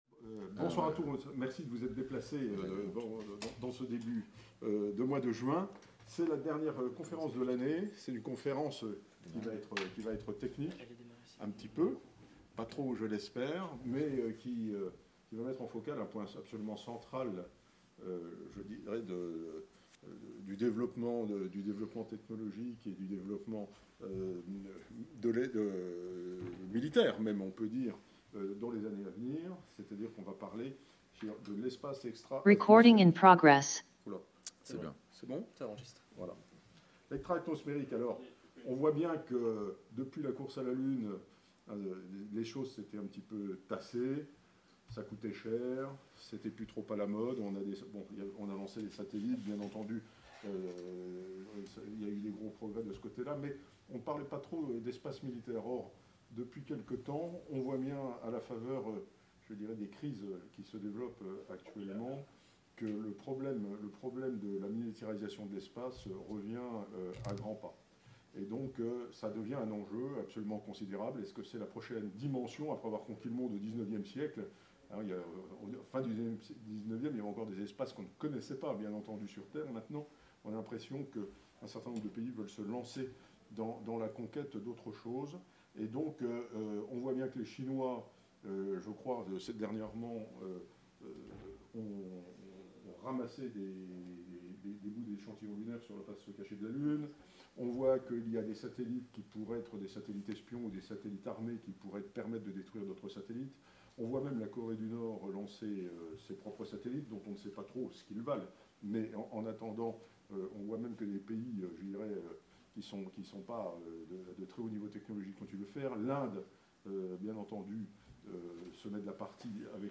Comme vous pouvez vous en rendre compte en les écoutant (ou réécoutant), nos intervenants ont eu à cœur d’exposer les enjeux de cette conquête qui porte en elle beaucoup d’espoir et de risques. Enregistrement de la conférence "Espace extra-terrestre" Pour mémoire l'invitation: Espace extra-atmosphérique